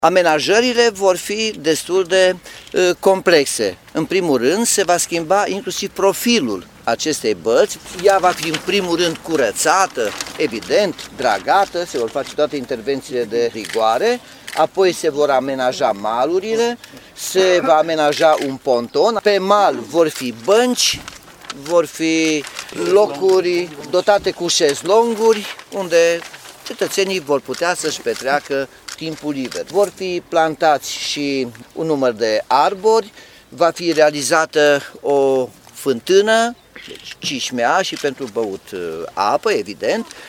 Balta Lămâița din cartierul timișorean Freidorf, ajunsă un focar de infecție în ultimii ani, va fi modernizată. Lucrarea costă aproximativ 570.000 de euro, bani proveniți din bugetul local, și tebuie terminată în opt luni. Primarul Nicolae Robu promite că, după finalizarea lucrărilor, balta va fi un loc plăcut de agrement: